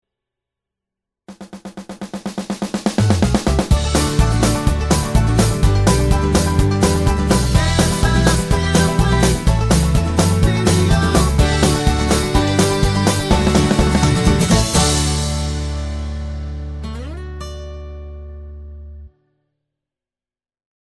Comedy/Novelty